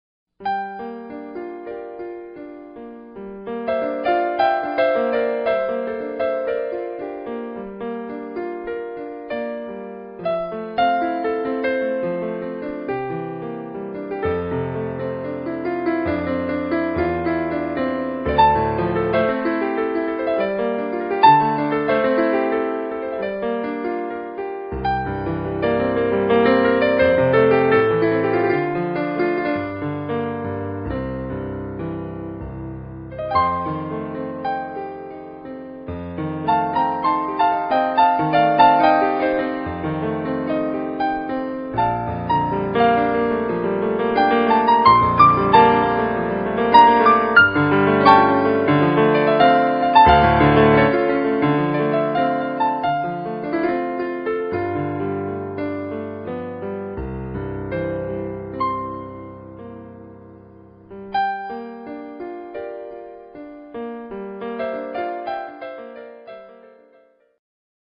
Solo Piano / Keyboard:
Classical